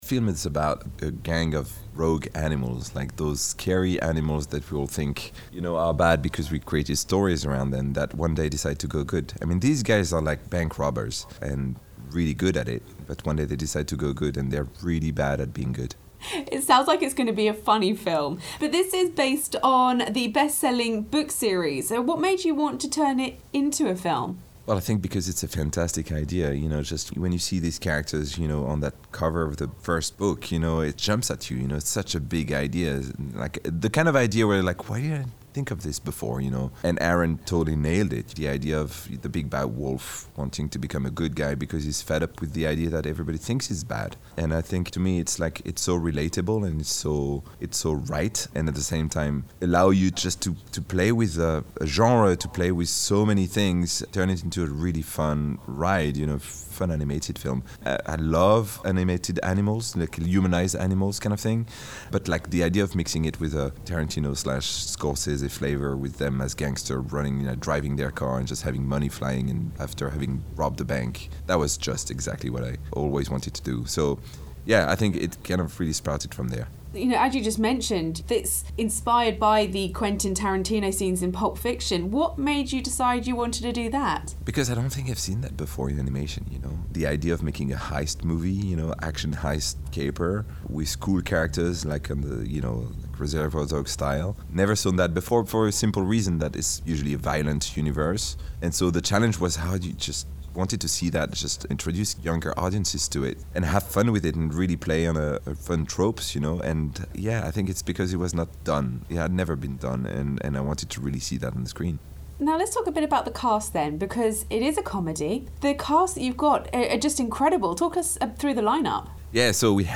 BFBS Radio bring you interviews and chats with some of the big names from the movie industry.